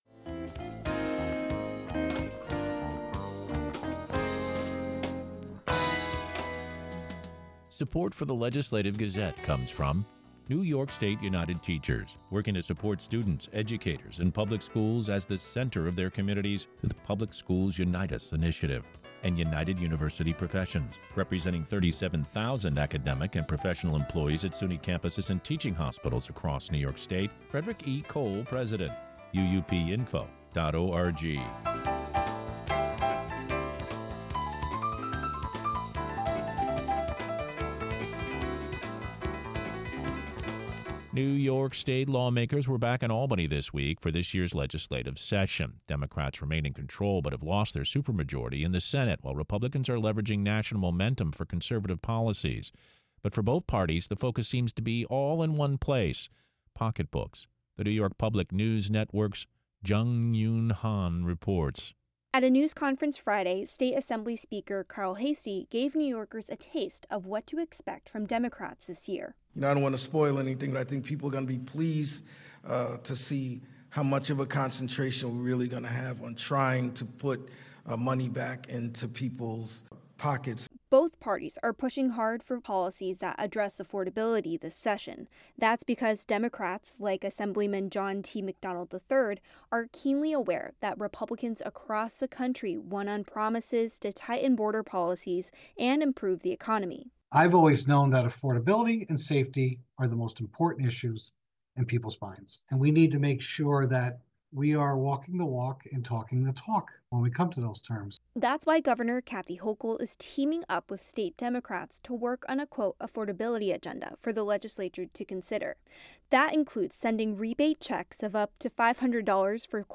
News